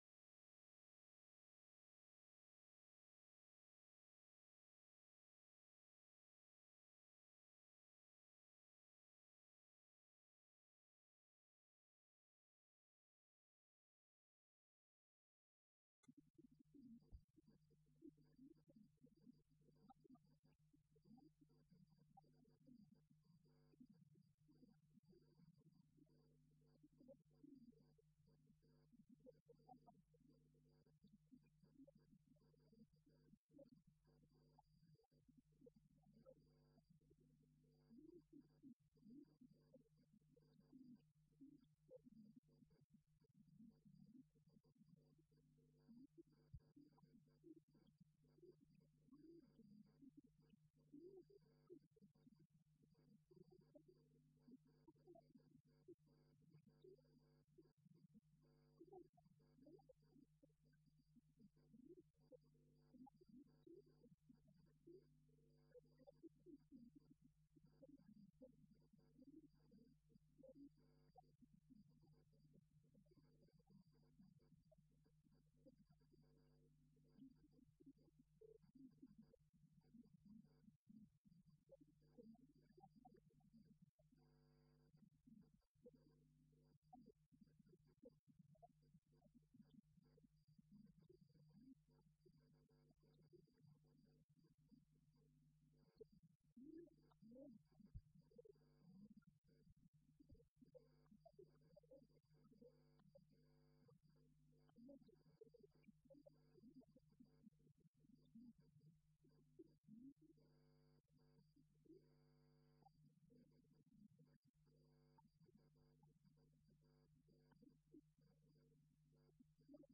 Session Traduction, langage et pensée philosophique Colloque L’Islam et l’Occident à l’époque médiévale.